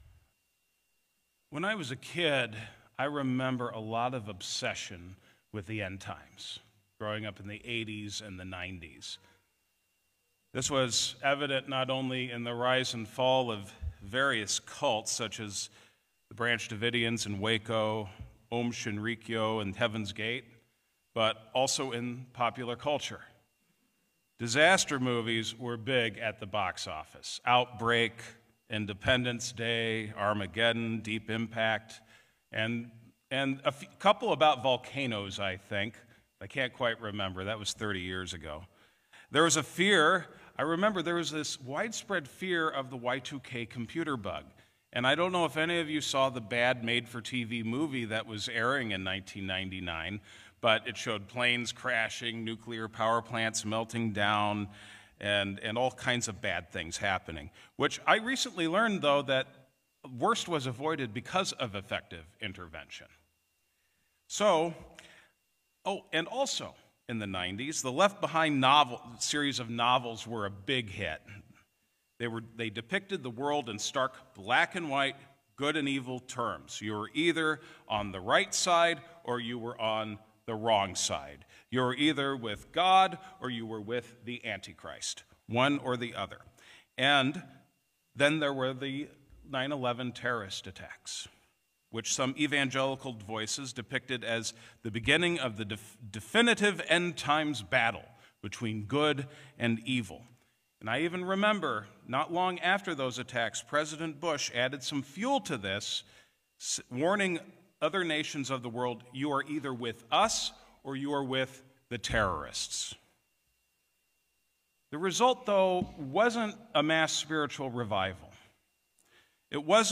Sermons | Shalom Lutheran Church